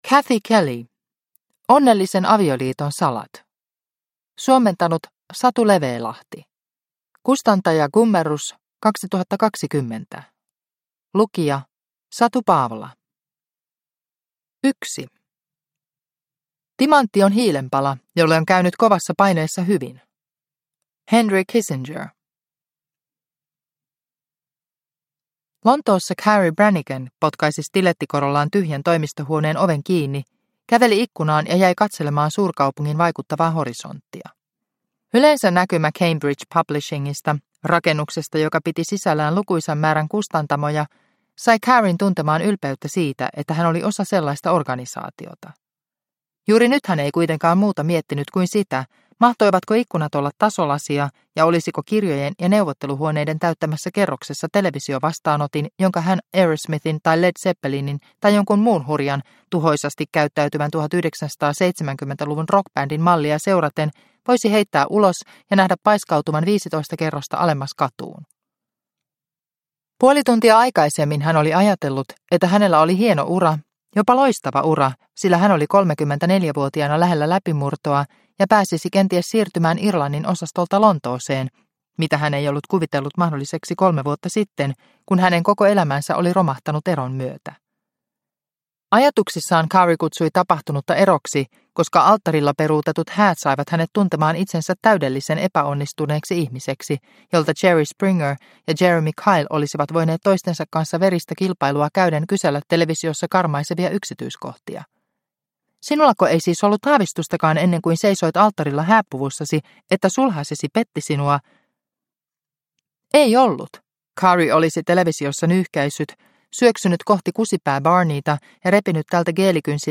Onnellisen avioliiton salat (ljudbok) av Cathy Kelly